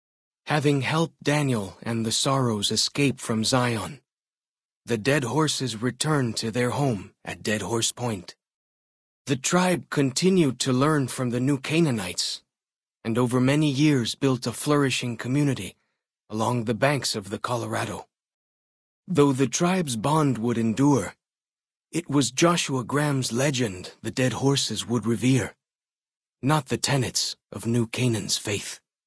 Category:Honest Hearts endgame narrations Du kannst diese Datei nicht überschreiben. Dateiverwendung Die folgende Seite verwendet diese Datei: Enden (Honest Hearts) Metadaten Diese Datei enthält weitere Informationen, die in der Regel von der Digitalkamera oder dem verwendeten Scanner stammen.